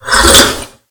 Drink.ogg